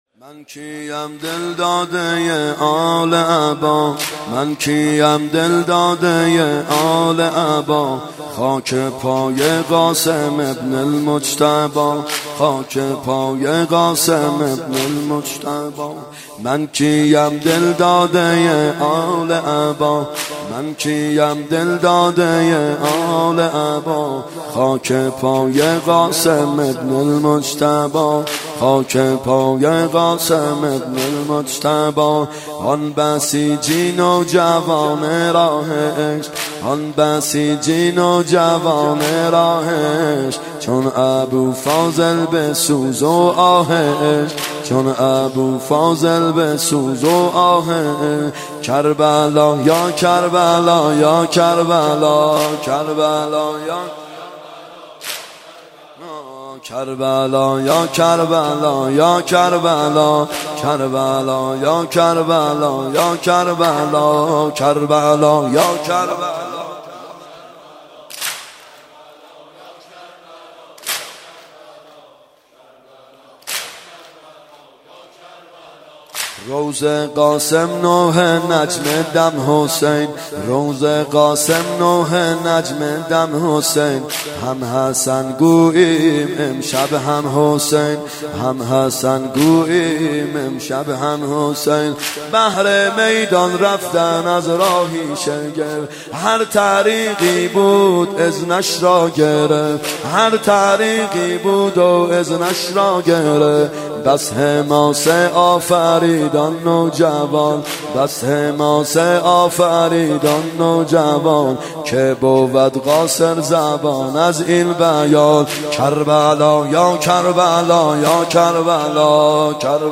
محرم 92 ( هیأت یامهدی عج)